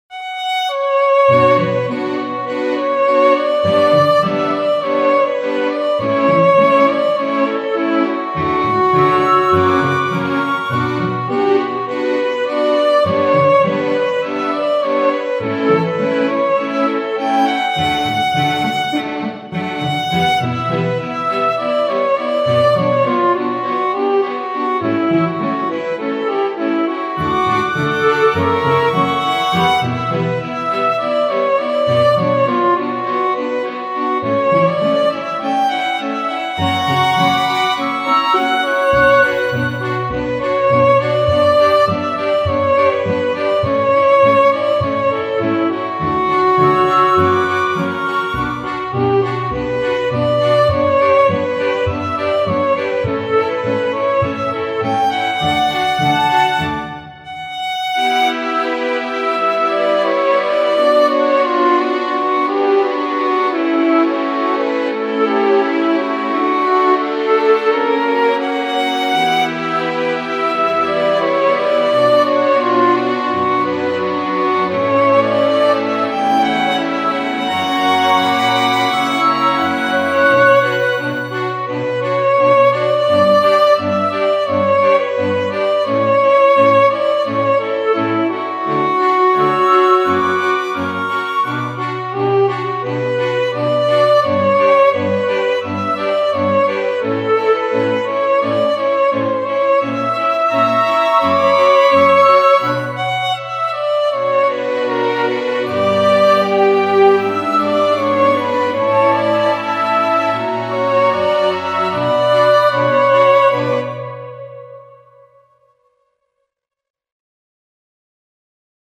ogg(L) しっとり バイオリン 切ない
バイオリンが切なく歌う。